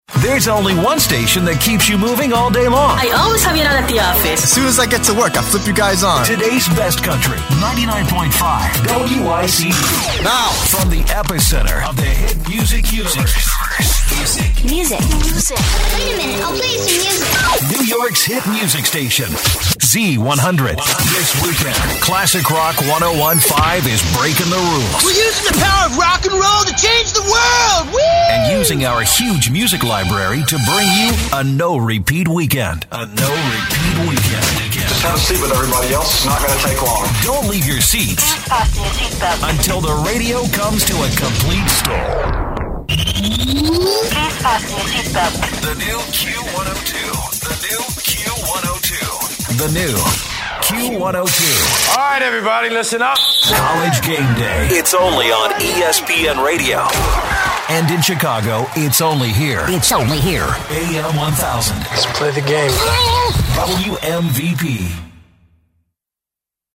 Male | Wooly Bugger Productions
mellifluous-articulate-experienced
Radio Imaging